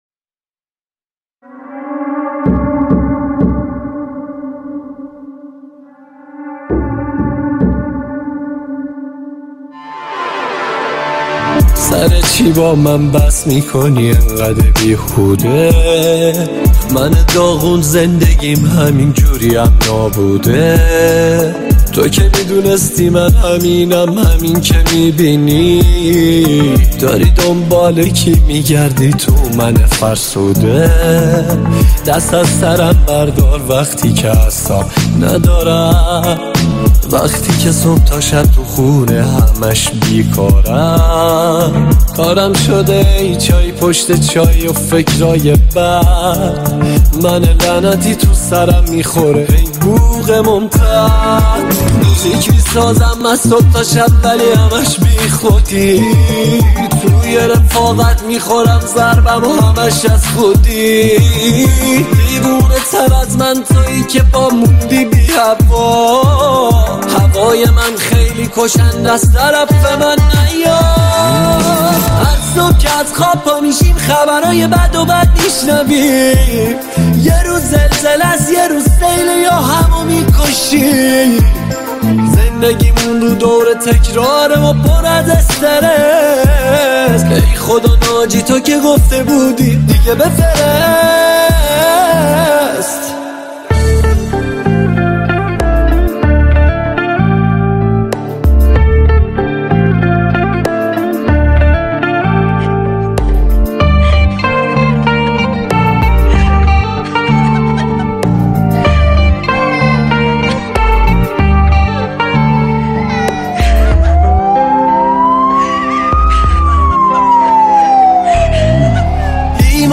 اين موزيك كاملا دلييييييييييه وبا سبك هميشگيم متفاوته